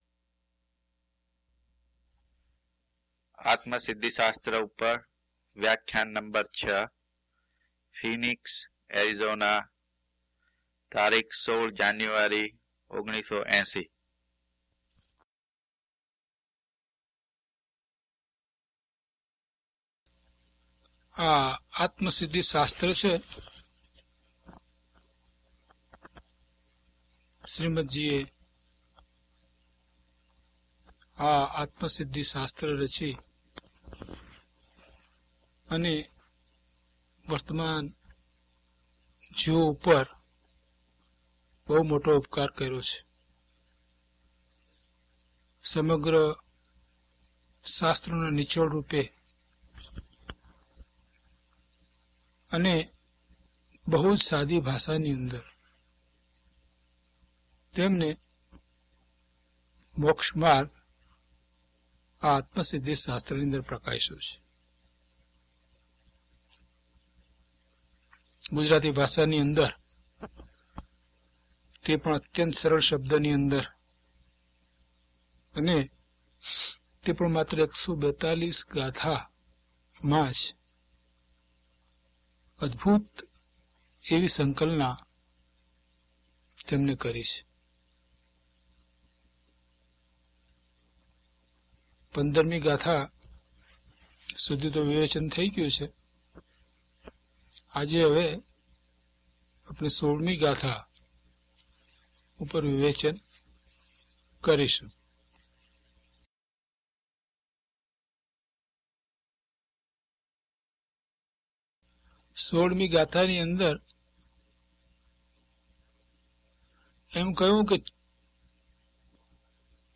DHP015 Atmasiddhi Vivechan 6 - Pravachan.mp3